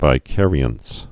(vī-kârē-əns, vĭ-)